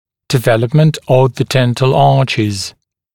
[dɪ’veləpmənt əv ðə ‘dent(ə)l ‘ɑːʧɪz][ди’вэлэпмэнт ов зэ ‘дэнт(э)л ‘а:чиз]формирование зубных рядов